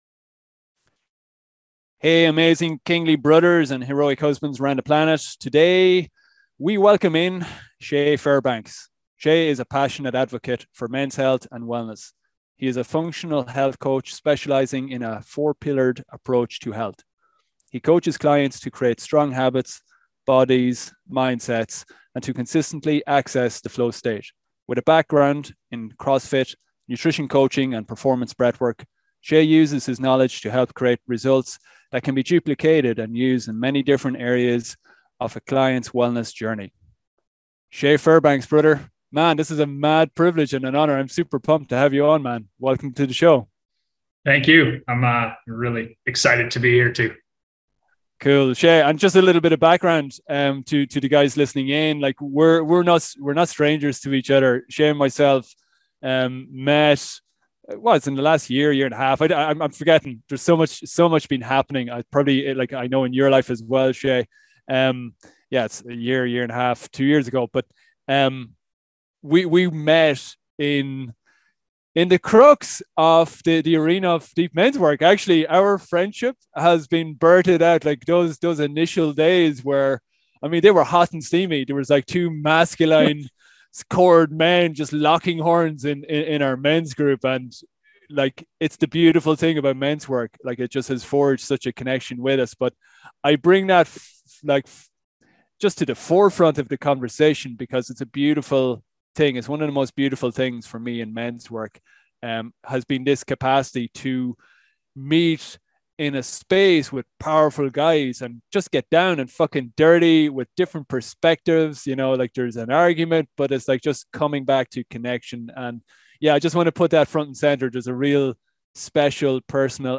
Enjoy a powerful conversation on creating epic health and finding flow in your life.